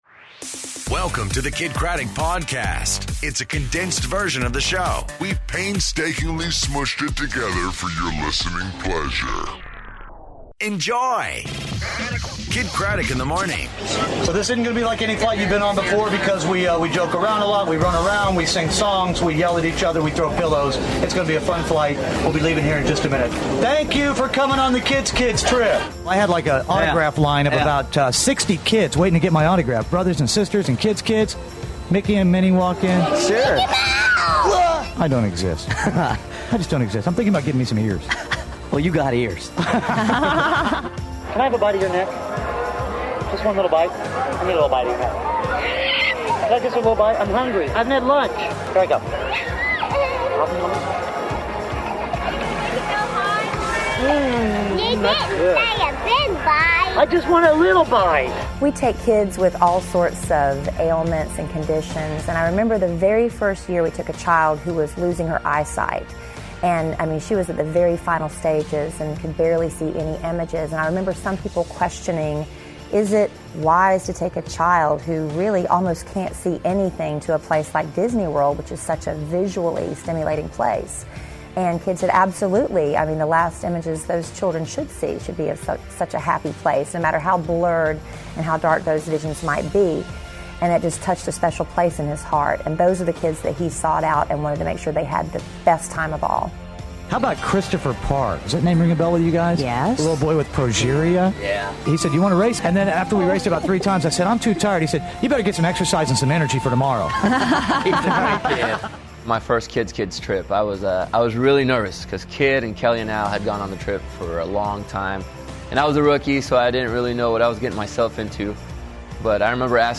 Day 1 From Walt Disney World!